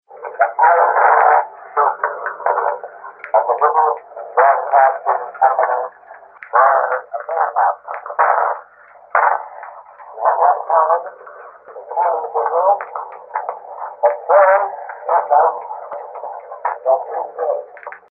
Then the music came on, from 3000 miles away: a dance band at the hotel, and a studio program featuring a violin and piano.
A Welcome From the London Announcer – Can make out a couple of words?
B1-London-Announcer.mp3